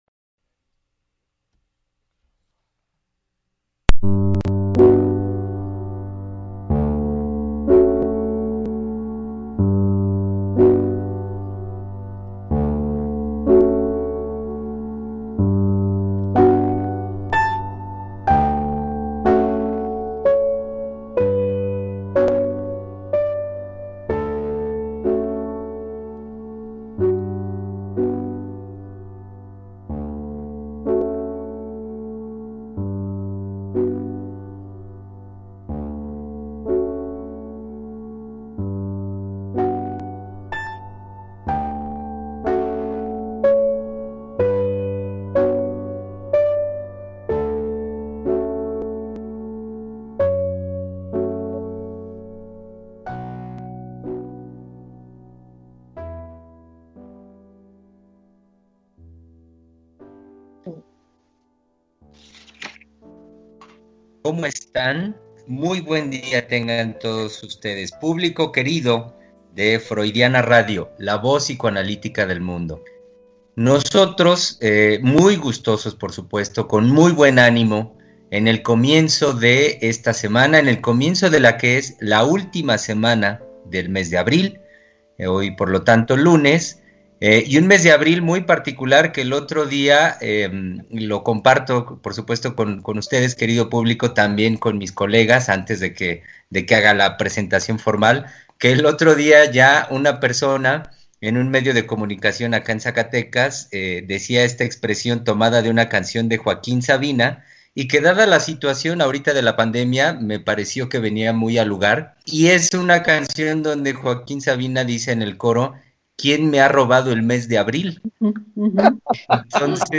“El duelo en el niño” Tres Mujeres Psicoanalistas Hablando de la Vida Cotidiana – Freudiana radio